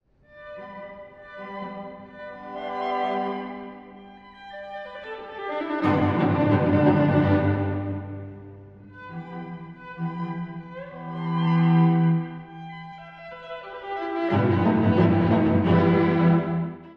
↑古い録音のため聴きづらいかもしれません！
曲調は、ボヘミア感たっぷり。
1,3,4楽章は幸福感のあるメロディーが多いです。
dvorak-sq13-1.mp3